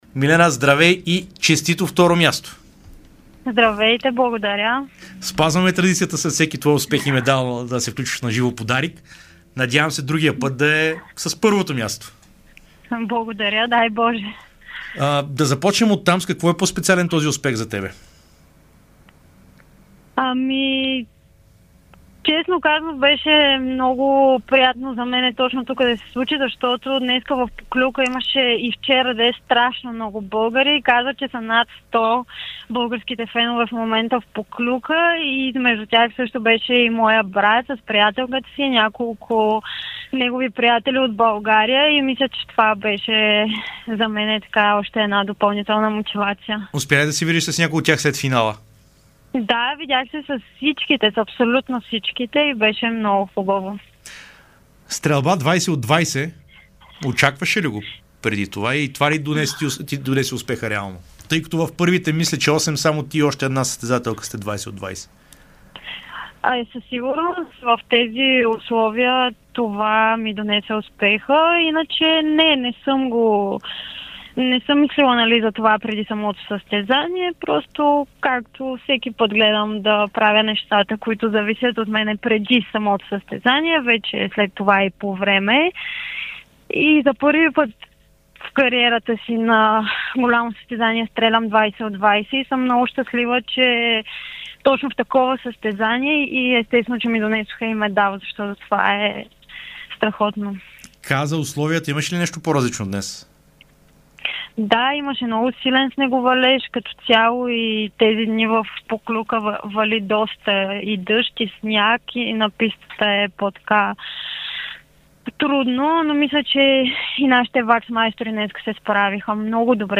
Биатлонистката Милена Тодорова спази традицията и след поредния си подиум говори на живо в ефира на Дарик радио. Българката завърши на второ място в масовия старт на 12.5 километра от Световната купа в словенския зимен център Поклюка